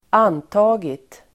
Uttal: [²'an:ta:git]